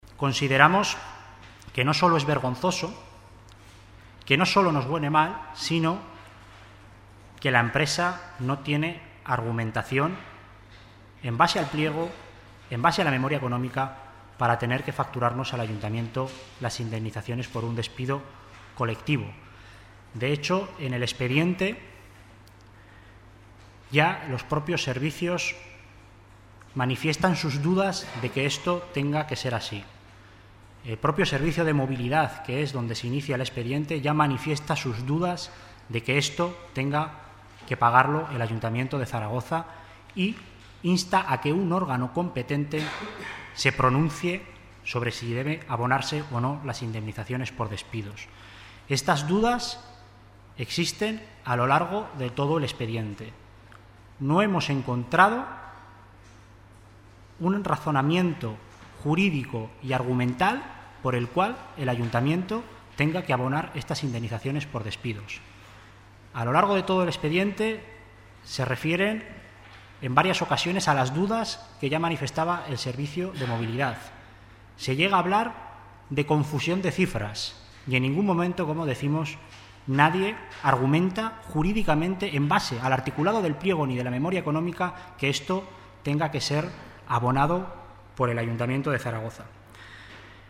Así lo ha explicado en rueda de prensa el Consejero de Servicios Públicos y Personal, Alberto Cubero, quien ha calificado de "vergonzosa" esta situación y ha dicho que "este Gobierno no admite el pago de despidos con dinero público, en una decisión adoptada, además, en el último minuto de la anterior legislatura".